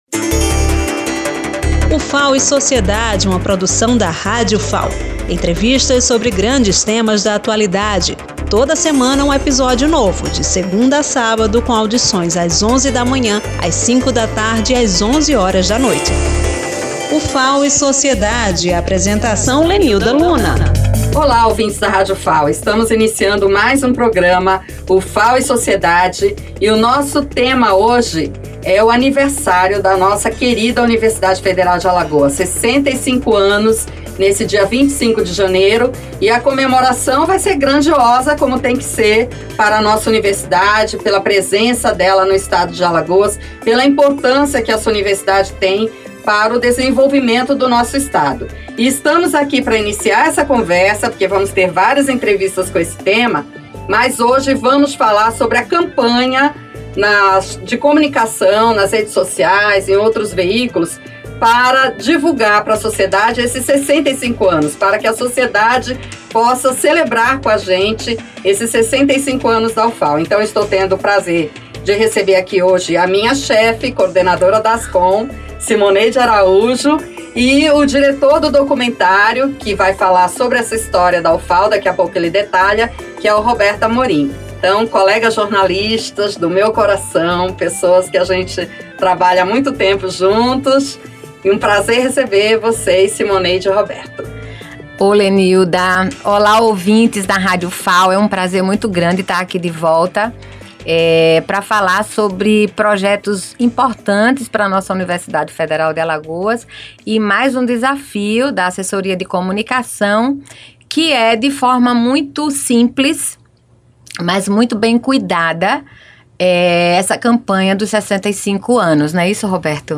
A entrevista inaugura uma sequência de conteúdos especiais que buscam valorizar a memória, a identidade e a contribuição histórica da Ufal para o desenvolvimento de Alagoas.